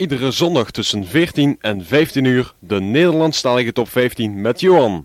Promotiespot